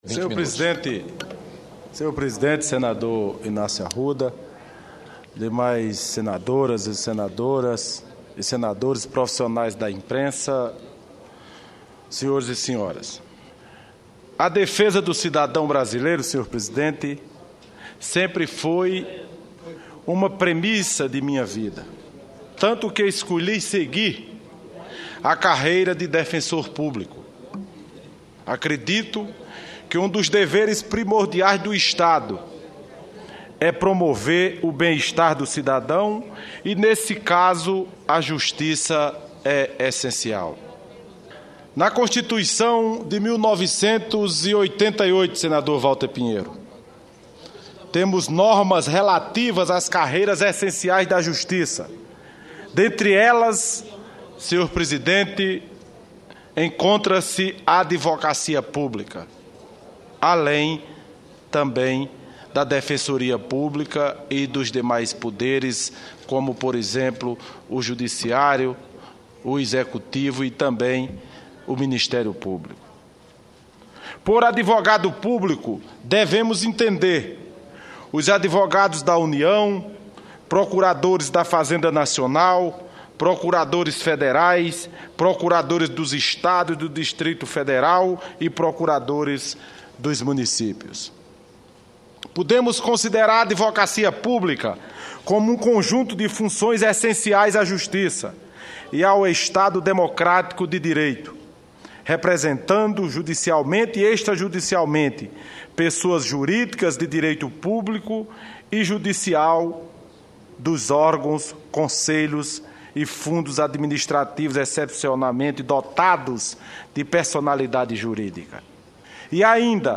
O senador Wilson Santiago (PMDB-PB) destacou a atuação dos advogados públicos, que segundo ele, asseguram a juridicidade da atuação administrativa e dos governos.